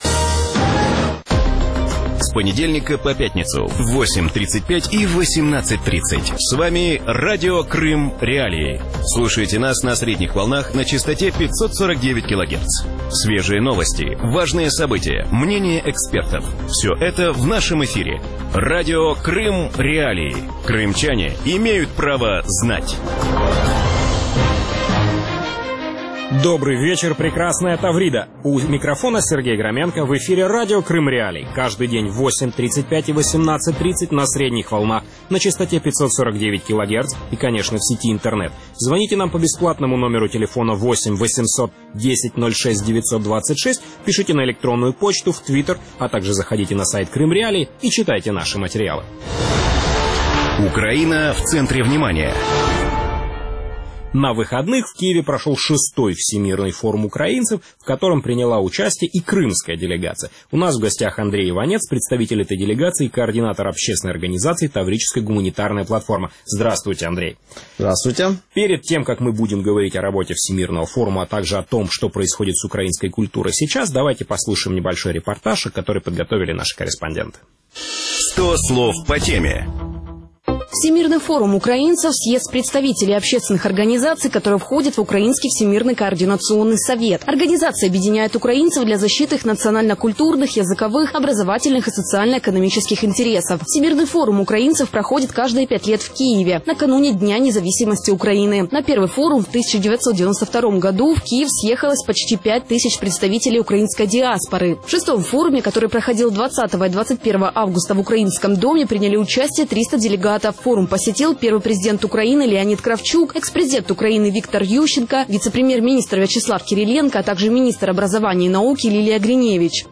В вечернем эфире Радио Крым.Реалии обсуждают итоги Всемирного форума украинцев и будущее украинской идентичности на полуострове.